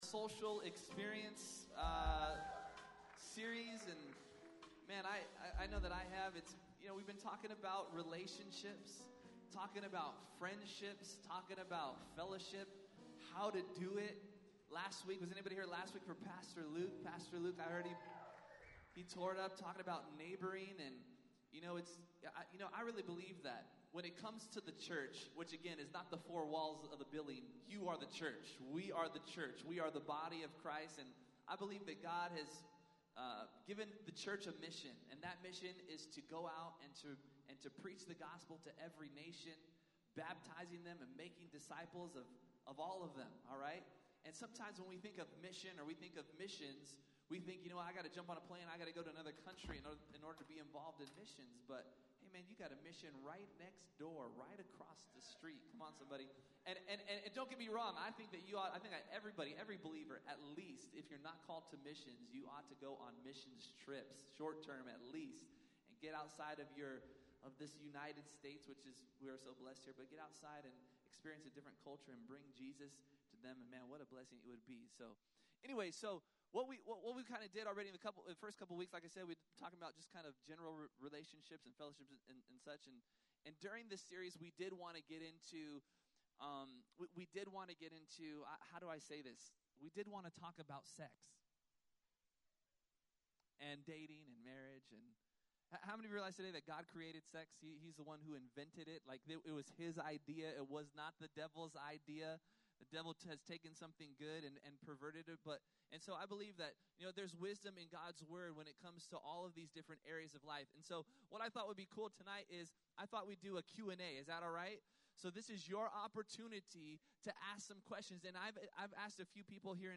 2_17_17_Social_Experience_Panel.mp3